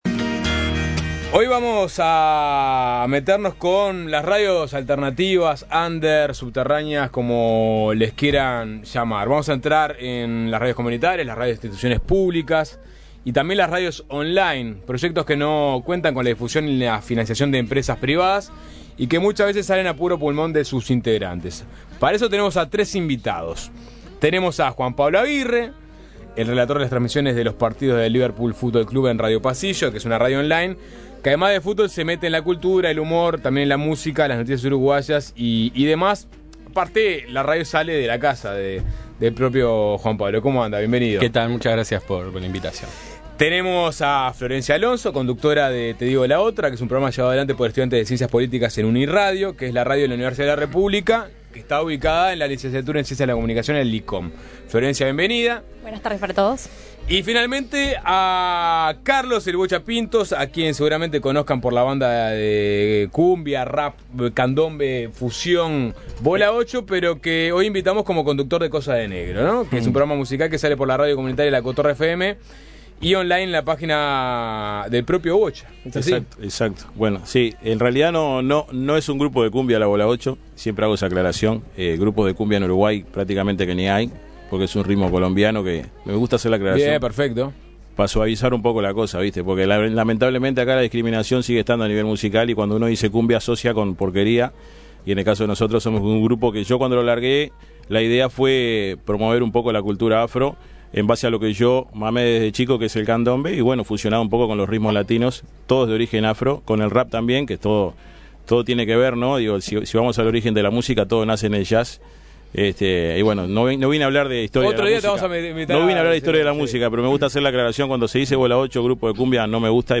Hoy visitaron el programa tres conductores de radios alternativas para contarnos cómo se vive el día a día en radios comunitarias, de instituciones públicas y online, y lo difícil que es llevar adelante proyectos que no cuentan con la difusión ni la financiación de empresas privadas.